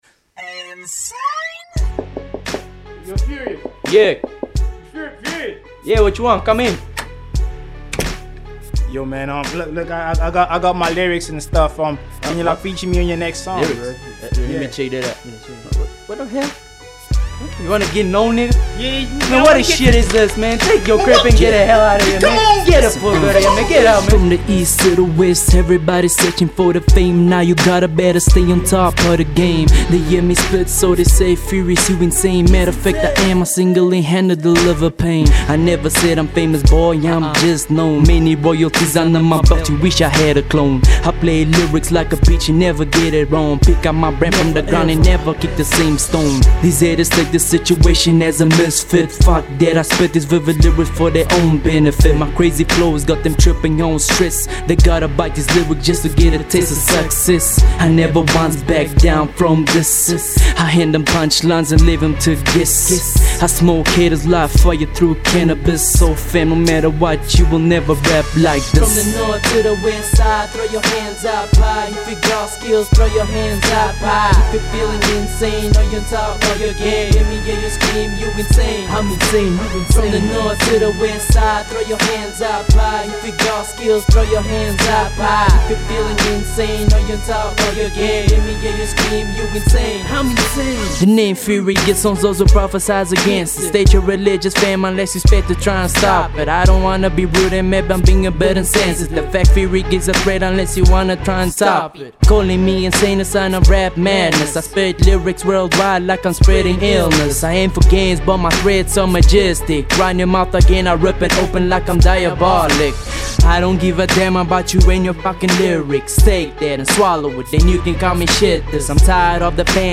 rap music
hip hop